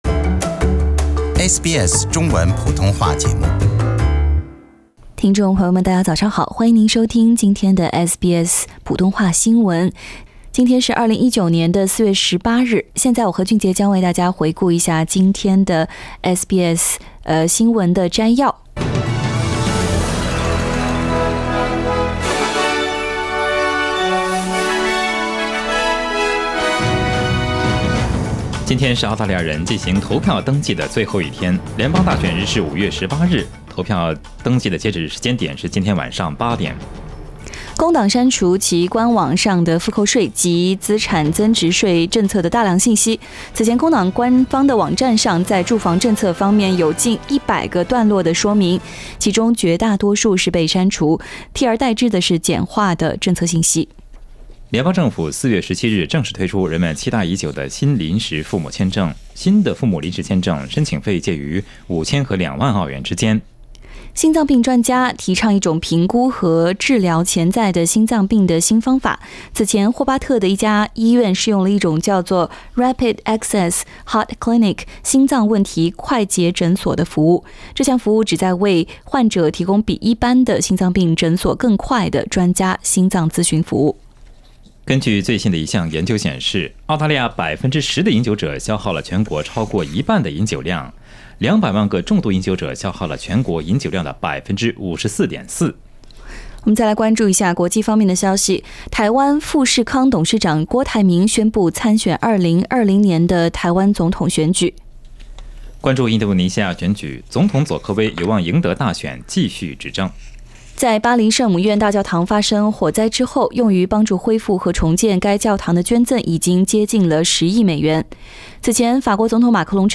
SBS早新闻 （4月18日）